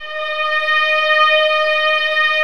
VIOLINS .3-R.wav